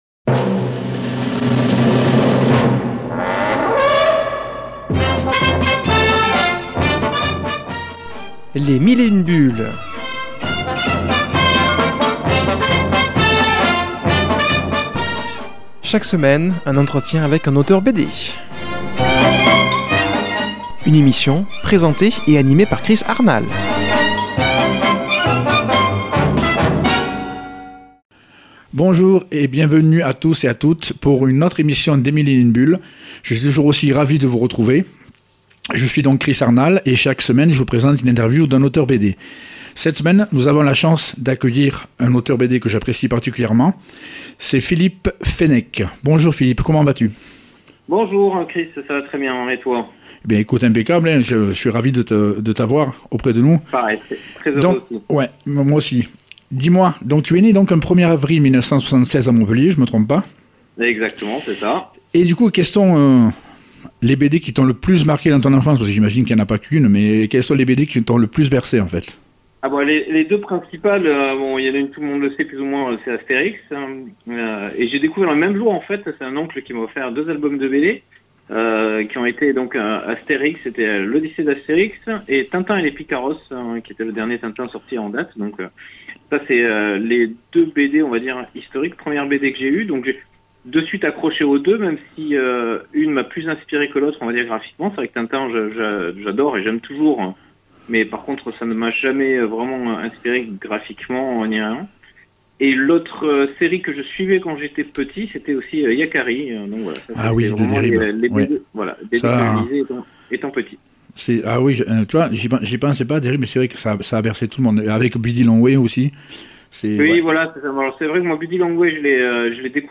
qui a comme invité au téléphone